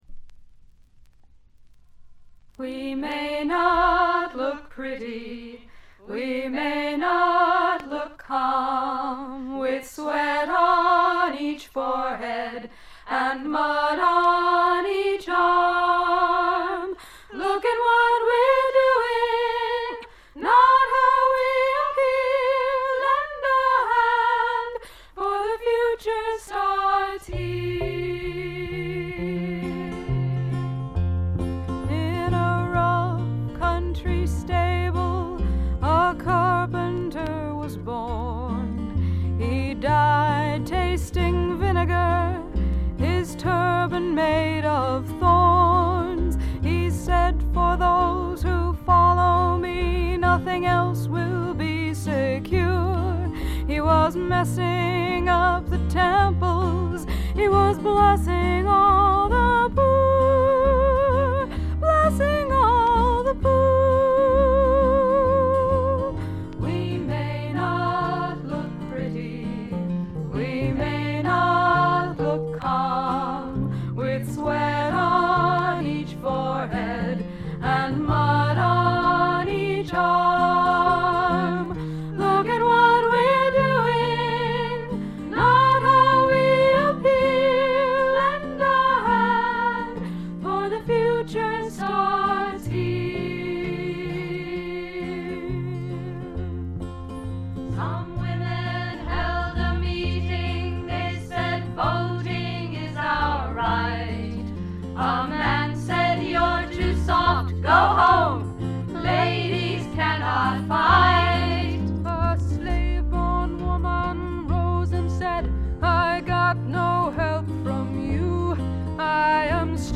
ホーム > レコード：米国 女性SSW / フォーク
弾き語りを中心にごくシンプルなバックが付く音作り、トラッドのアカペラも最高です。
Vocals, Guitar, Autoharp, Recorder